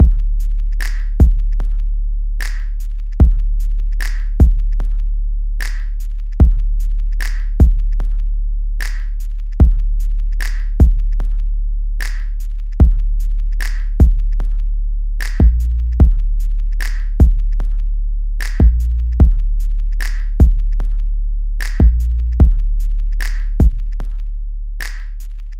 西游记风格的鼓点循环2
标签： 75 bpm Trap Loops Drum Loops 4.31 MB wav Key : Unknown
声道立体声